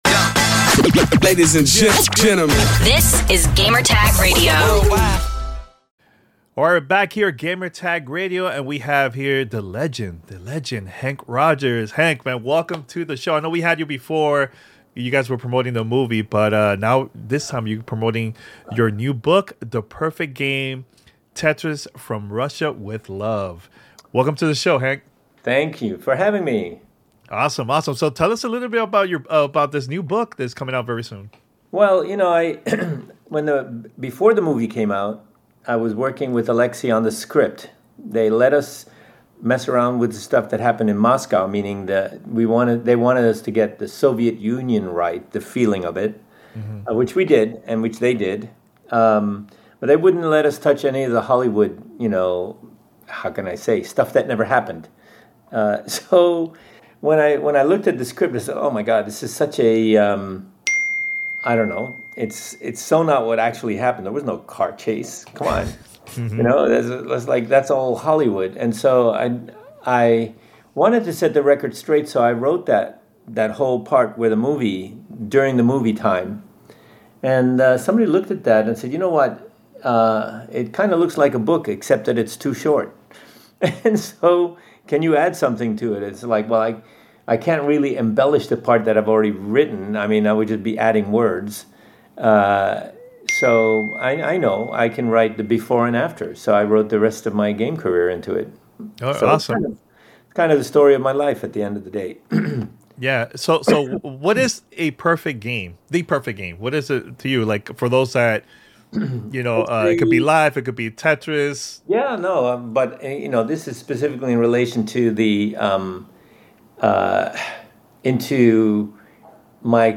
The Tetris Story: A Conversation with Henk Rogers